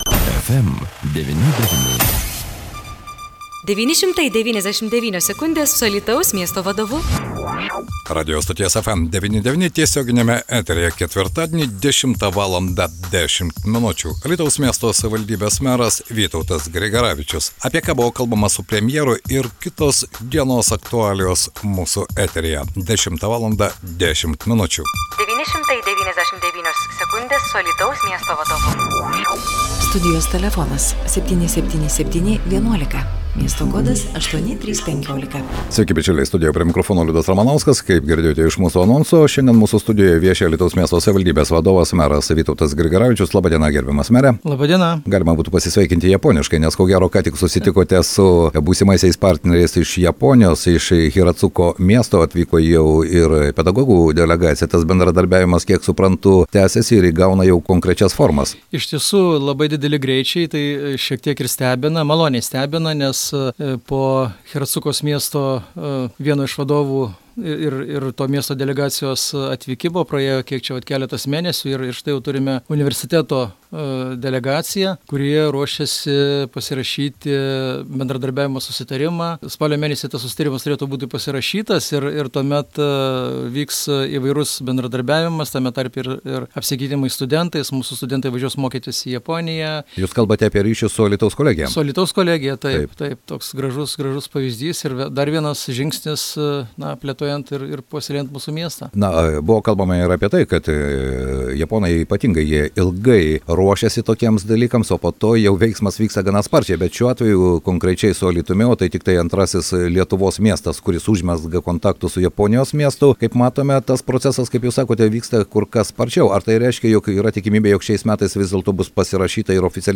Meras į studiją atskubėjo po Japonijos švietimo srities specialistų iš Hiratsuka delegacijos priėmimo. Vytautas Grigaravičius paminėjo, kad bendradarbiavimas švietimo srityje prasidės Alytaus kolegijoje.